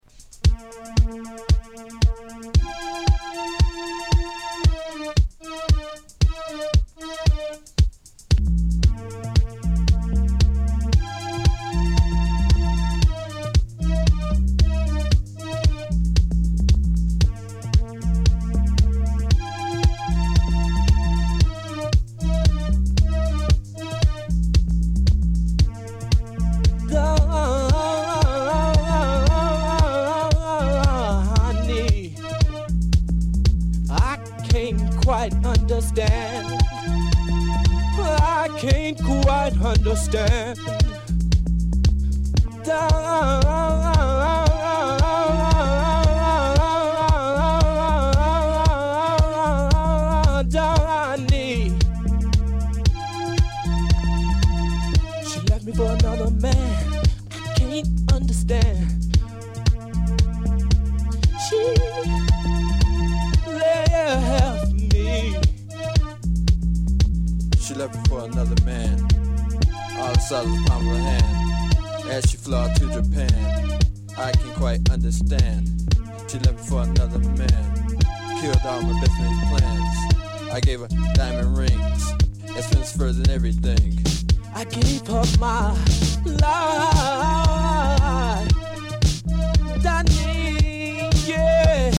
Mastered from vinyl, including original label art.